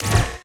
SCIMisc_Sci Fi Shotgun Reload_06_SFRMS_SCIWPNS.wav